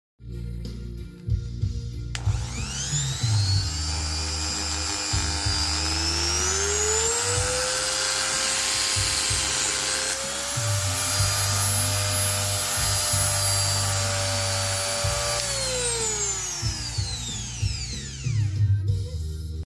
Tes Kecepatan Mini Die Grinder Sound Effects Free Download